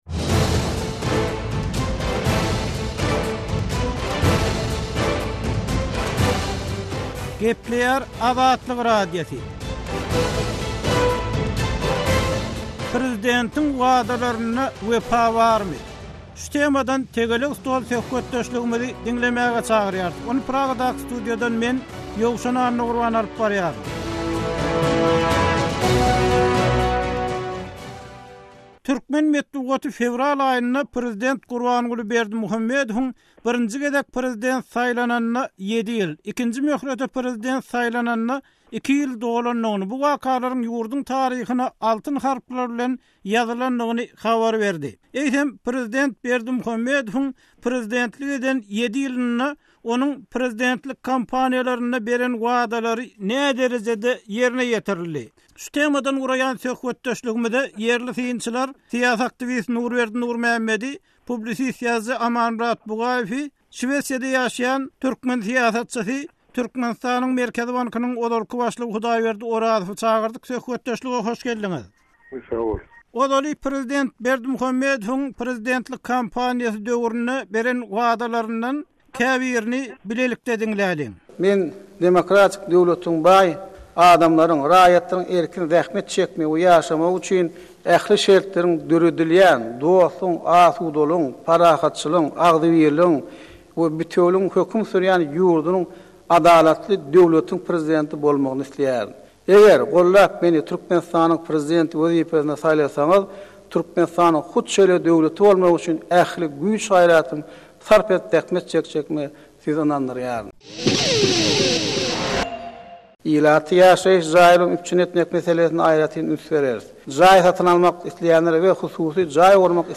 Tegelek stol: Prezidentiň wadalary ýerine ýetirilýärmi?